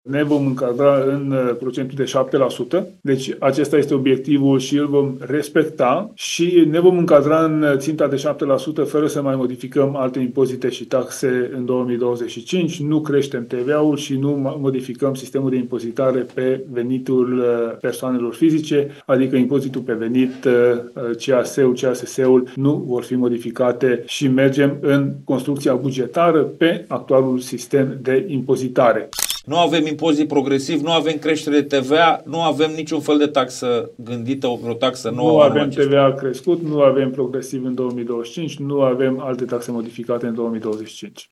Tanczos Barna, într-o conferință a grupului „Deloitte”: „Nu avem TVA crescut, nu avem progresiv în 2025, nu avem alte taxe modificate în 2025”